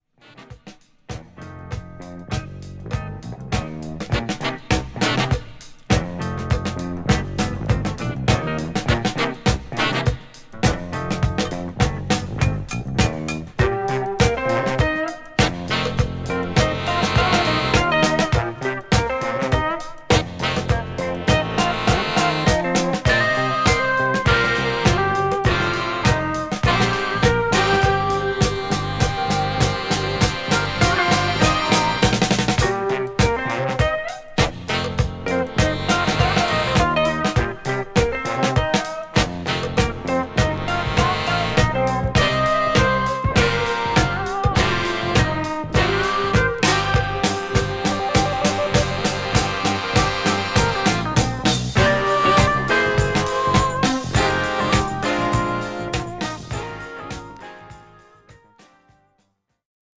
JAZZ / JAZZ FUNK / FUSION